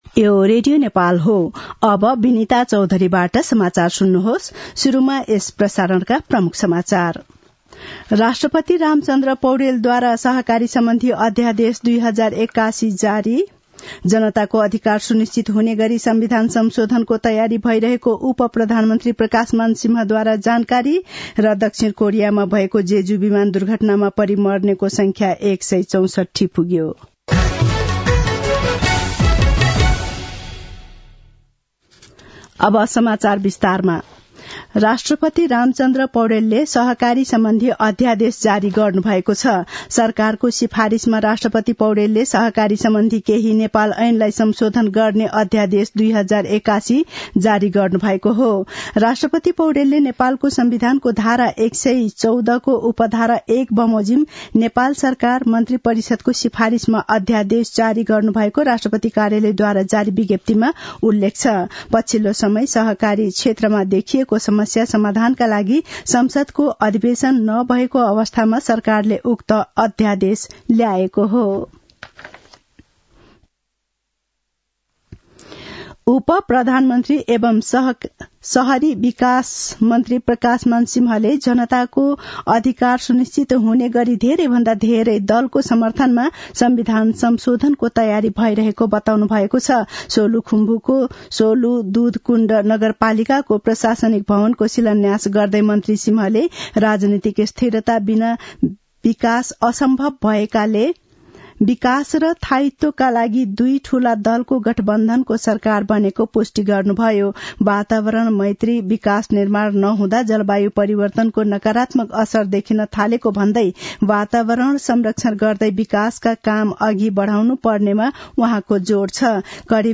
दिउँसो ३ बजेको नेपाली समाचार : १५ पुष , २०८१
3-pm-nepali-news-1-16.mp3